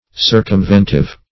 Search Result for " circumventive" : The Collaborative International Dictionary of English v.0.48: Circumventive \Cir`cum*vent"ive\, a. Tending to circumvent; deceiving by artifices; deluding.